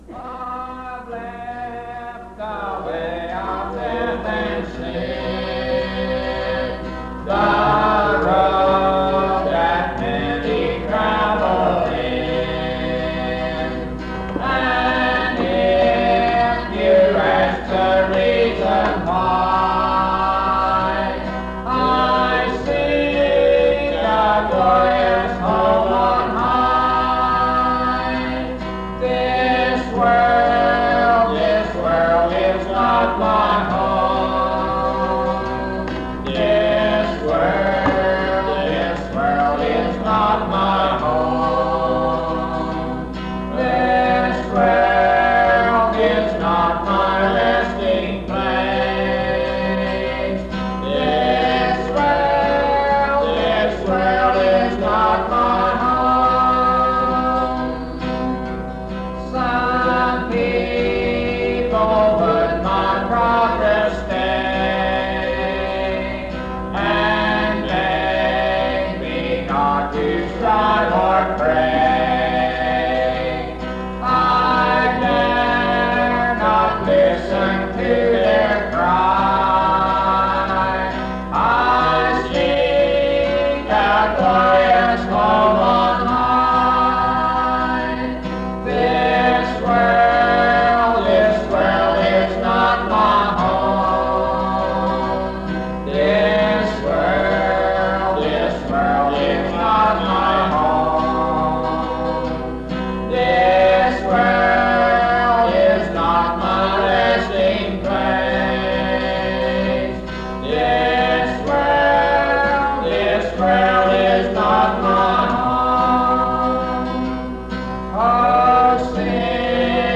This recording is from the Monongalia Tri-District Sing. Church of the Bretheren, Morgantown, Monongalia County, WV, track 138J.